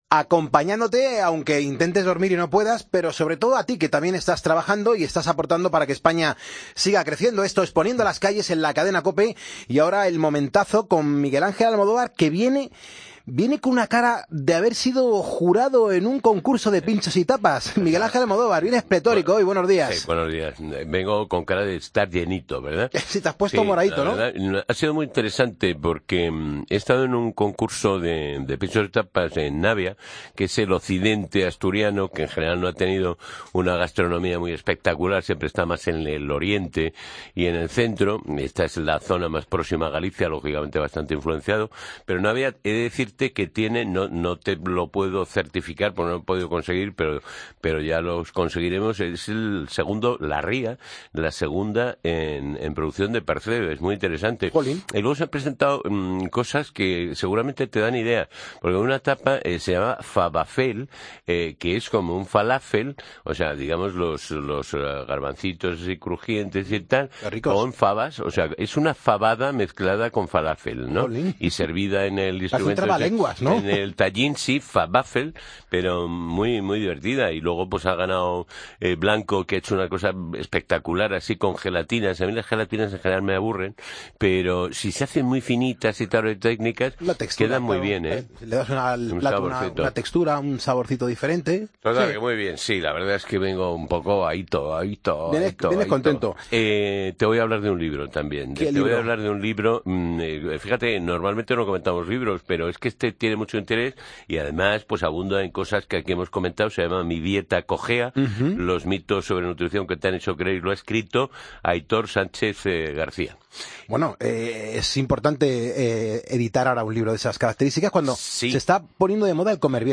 Con el experto en nutrición